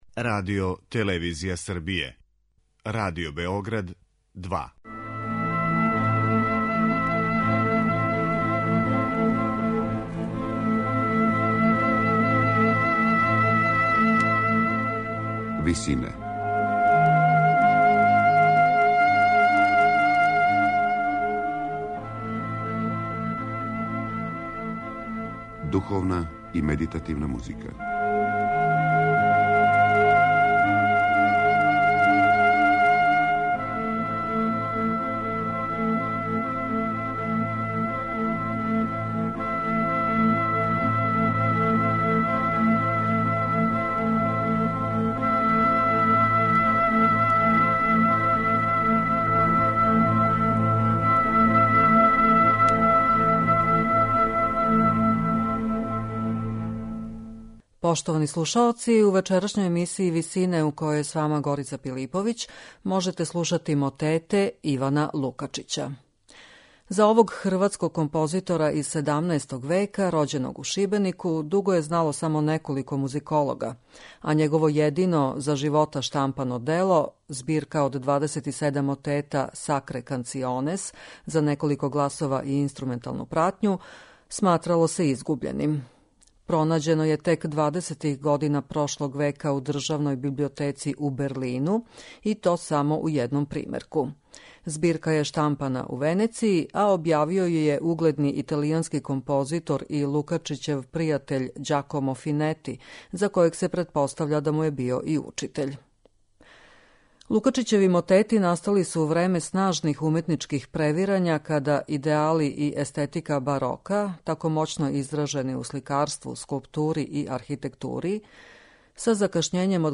У емисији Висине, можете слушати мотете овог хрватског композитора из XVII века.
медитативне и духовне композиције